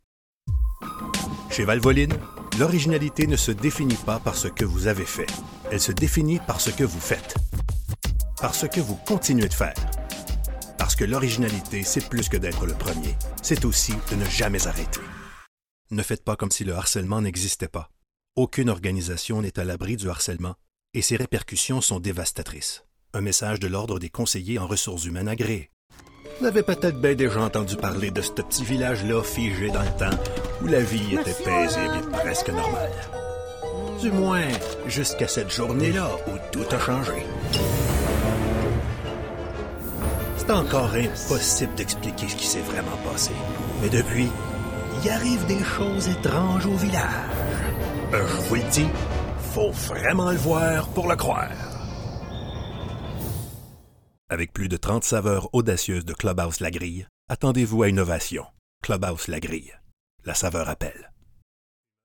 French (Canadian)
Authoritative
Believable
Warm